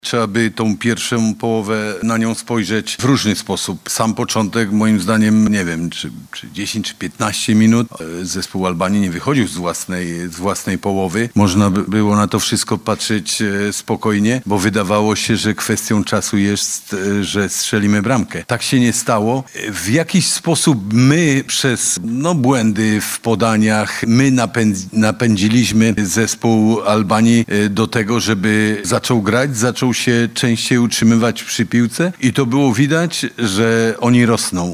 – Podsumował pierwszą połowę selekcjoner Jan Urban